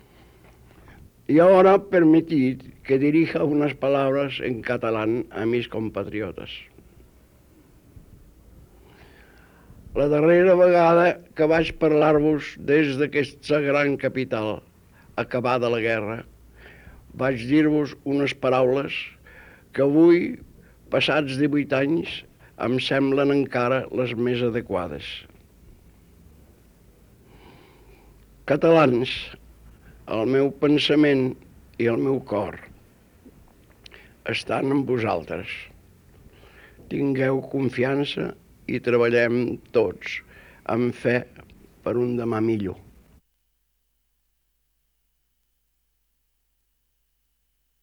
Paraules en català del músic Pau Casals.